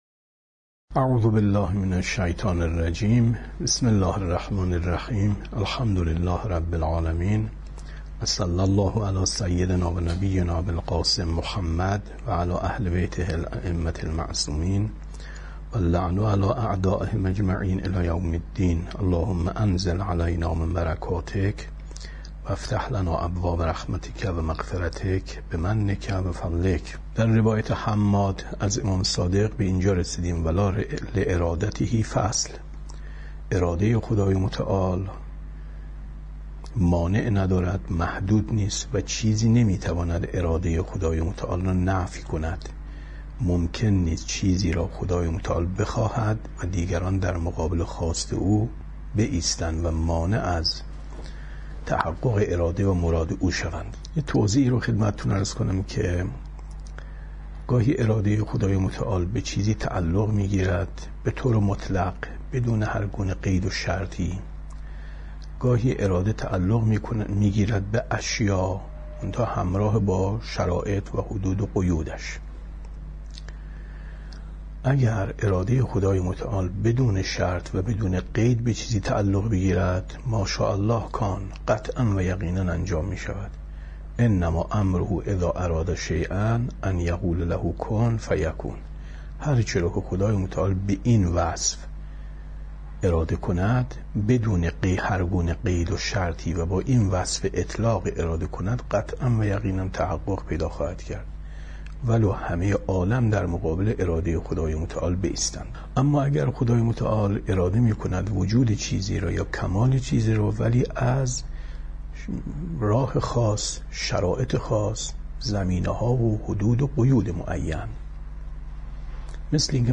کتاب توحید ـ درس 41 ـ 12/ 10/ 95